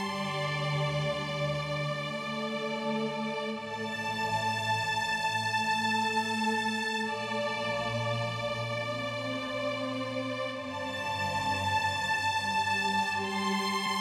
VTS1 Universe Kit 137BPM Choirstring WET.wav